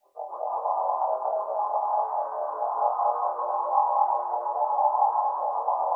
Index of /90_sSampleCDs/Spectrasonic Distorted Reality 2/Partition D/07 SCI-FI 1
RECTIFIER.wav